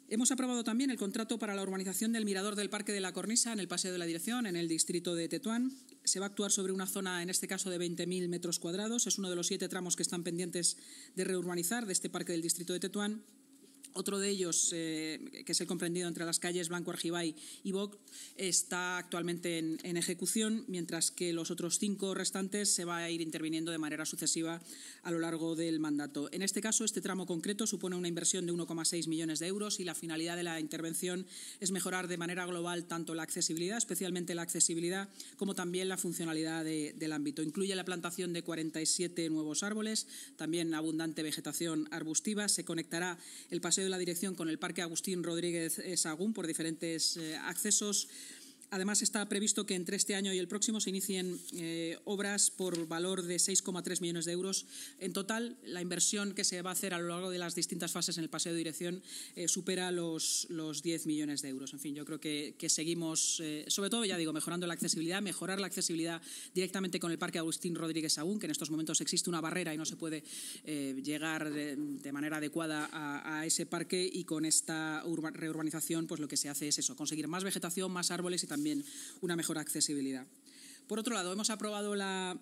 El Ayuntamiento de Madrid ha aprobado el contrato para la urbanización del mirador del parque de la Cornisa del paseo de la Dirección. Así lo ha anunciado en rueda de prensa la vicealcaldesa y portavoz municipal, Inma Sanz, tras la reunión semanal de la Junta de Gobierno.